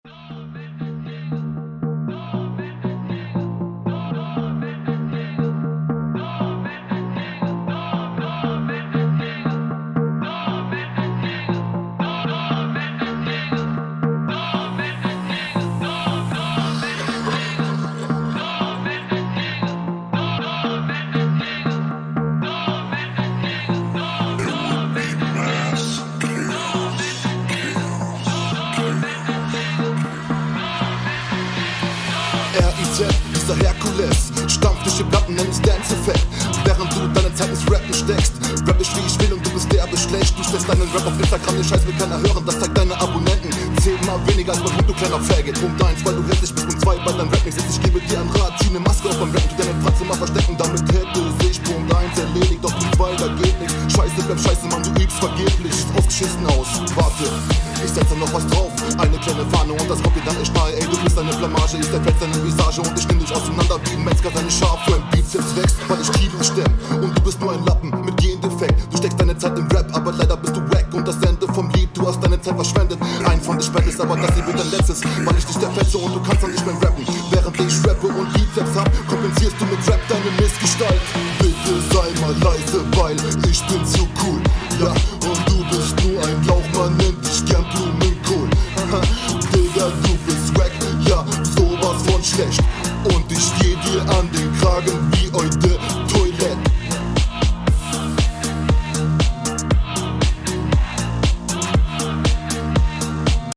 deine Stimme ist bisschen zu leise.